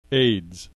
click this icon to hear the preceding term pronounced   (Acquired Immunodeficiency Deficiency Syndrome)